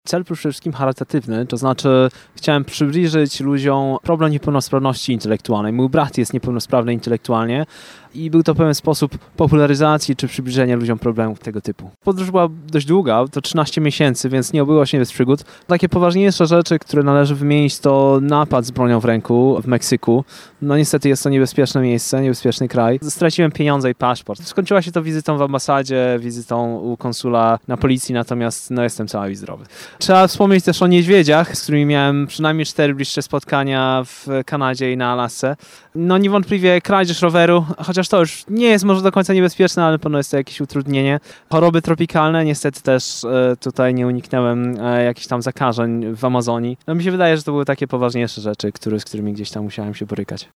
W rozmowie z Twoim radiem opowiada o trudach wyprawy oraz o jej celu.